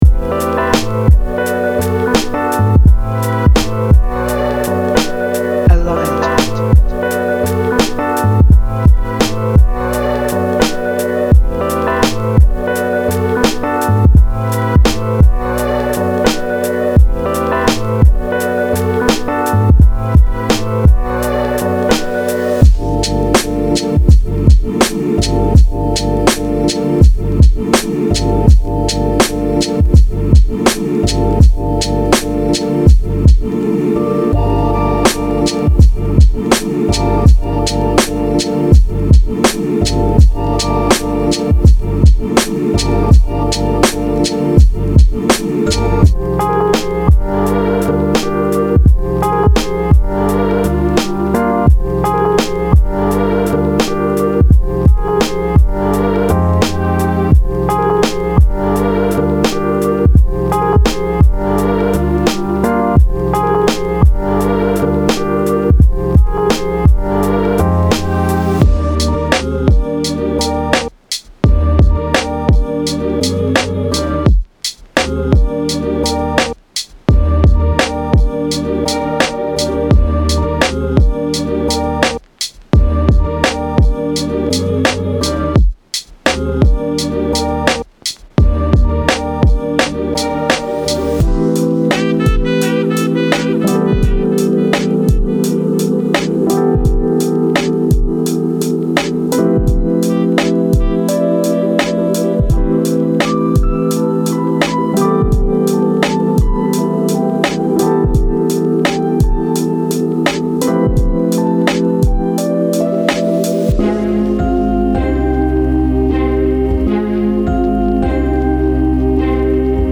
Genre:Hip Hop
ソウルサンプル、チョップ、ドラム。
さらに、思わず首を振りたくなるドラムも収録されています。